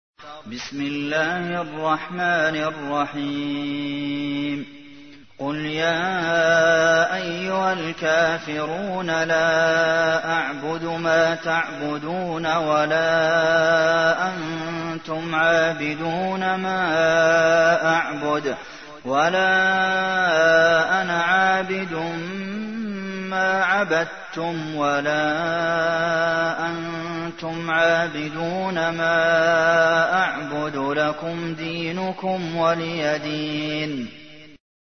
تحميل : 109. سورة الكافرون / القارئ عبد المحسن قاسم / القرآن الكريم / موقع يا حسين